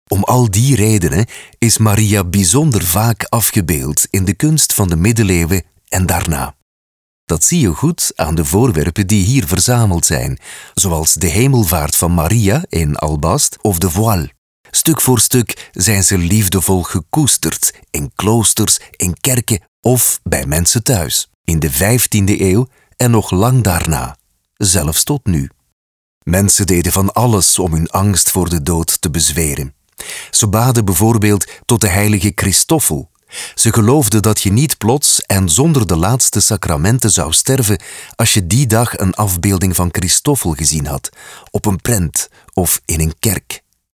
Cálida, Profundo, Seguro, Maduro, Accesible
Audioguía